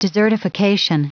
Prononciation du mot desertification en anglais (fichier audio)
Prononciation du mot : desertification